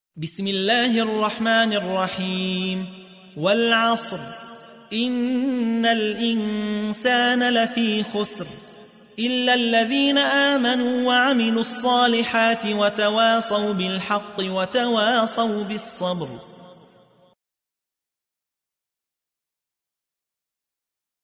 تحميل : 103. سورة العصر / القارئ يحيى حوا / القرآن الكريم / موقع يا حسين